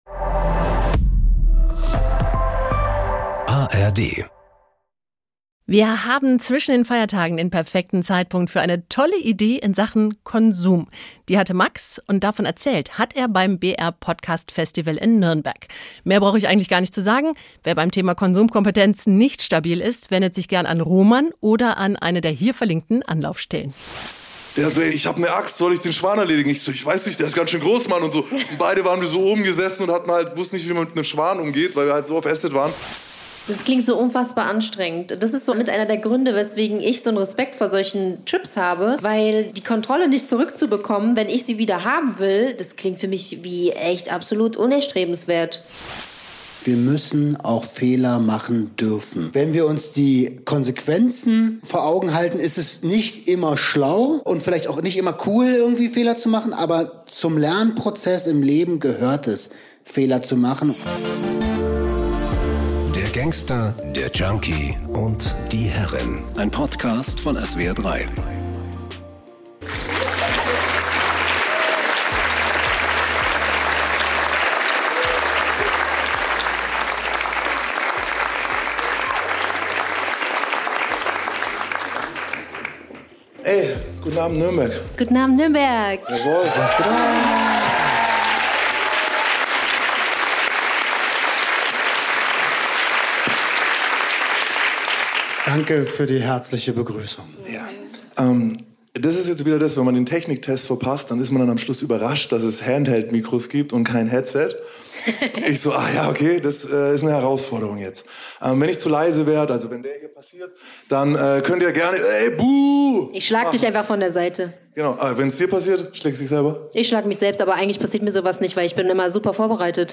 live vom BR Podcastfestival 2024 1:06:42 Play Pause 4h ago 1:06:42 Play Pause Später Spielen Später Spielen Listen Gefällt mir Geliked 1:06:42 Diese Folge wurde live beim BR Podcastfestival in Nürnberg am 17.10.2024 aufgenommen.